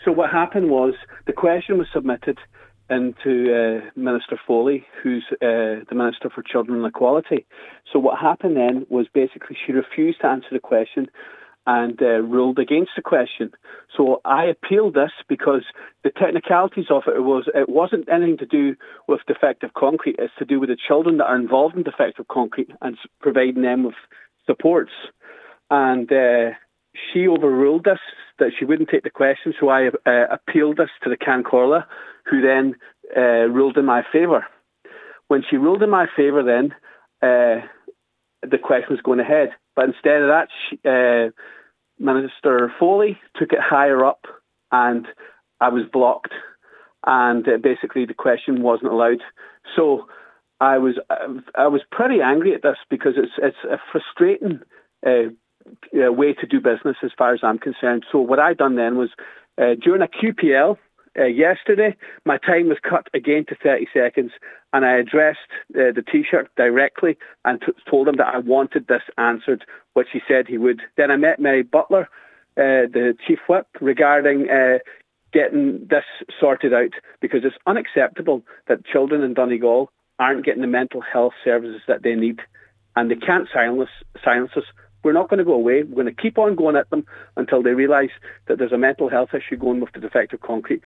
However, the Department said the issue falls under Housing, not Children, prompting criticism from Deputy Ward: